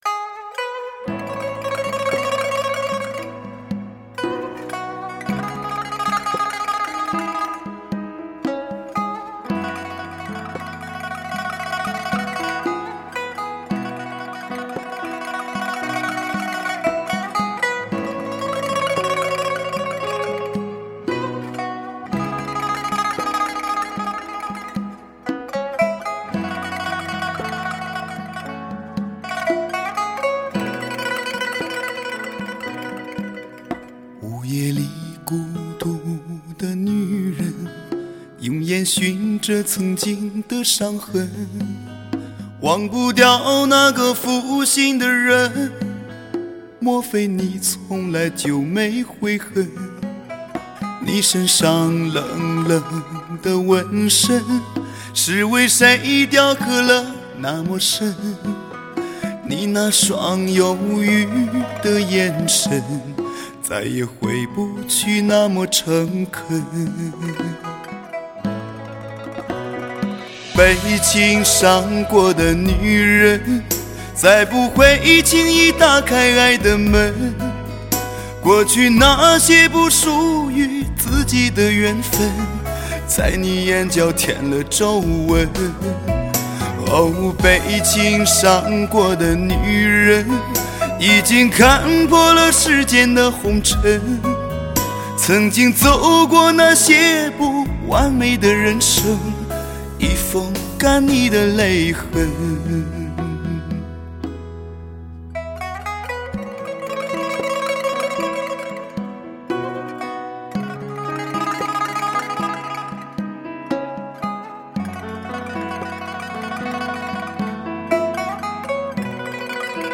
迷人奔放的中低音，浓浓情释时下经典，颇具个人色彩的新浪漫主义作品，